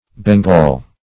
Bengal \Ben*gal"\, prop. n.